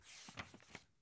book-flip02.ogg